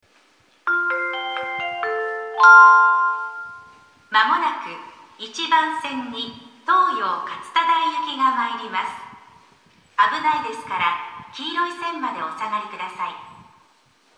駅放送
2014年2月20日頃より接近メロディ付きの新放送に切り替わりました（到着放送は3月9日頃追加）。